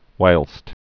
(wīlst, hwīlst)